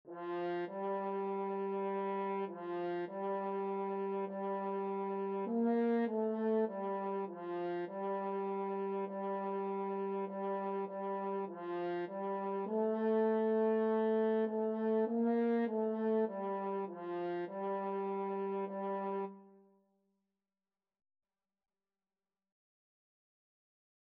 4/4 (View more 4/4 Music)
F4-Bb4
Beginners Level: Recommended for Beginners
Instrument:
French Horn  (View more Beginners French Horn Music)
Classical (View more Classical French Horn Music)